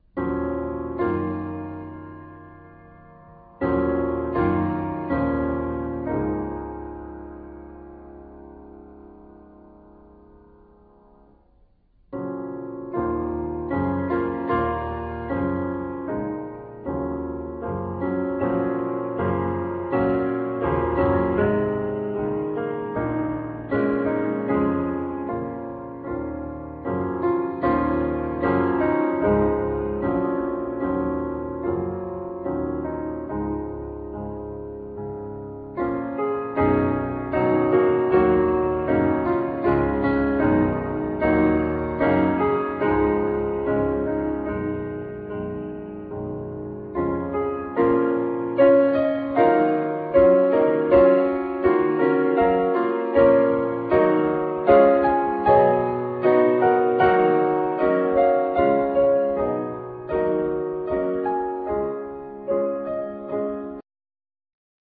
Piano
Viola
Cello